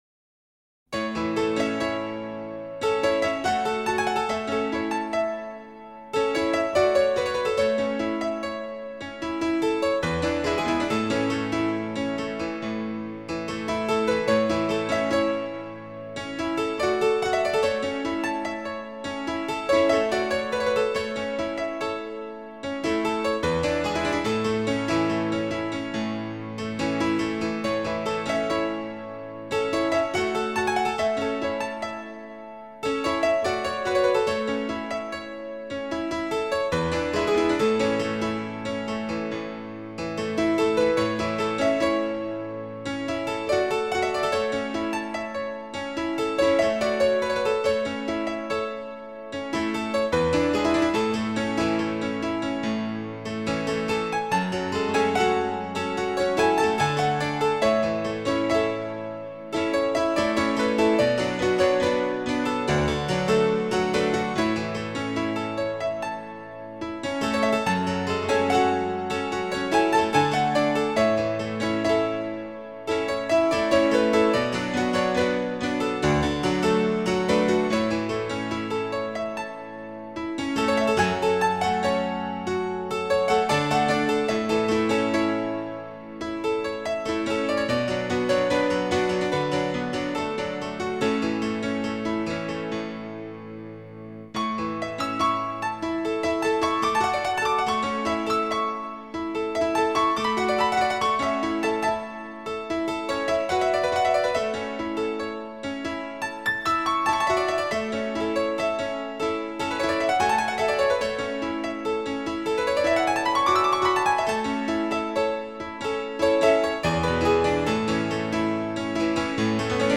僅低音質壓縮 , 供此線上試聽